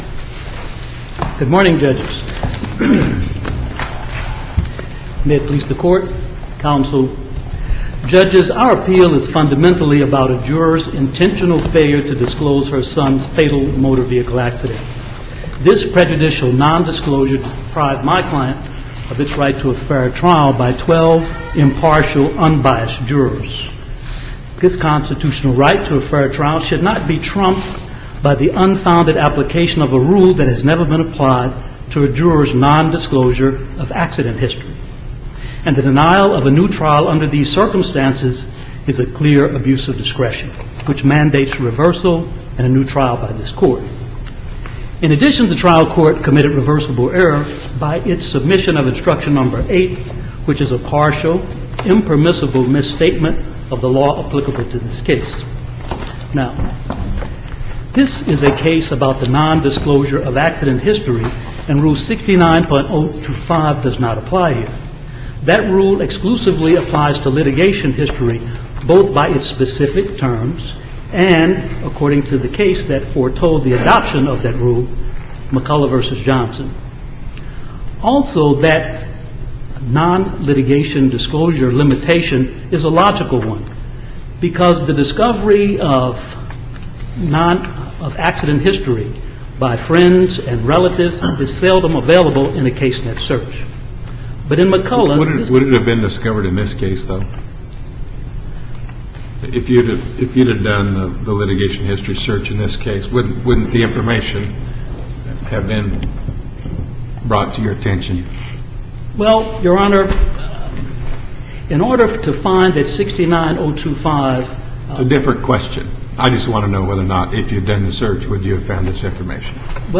MP3 audio file of arguments in SC96032
Challenge regarding untimely motion for postconviction relief Listen to the oral argument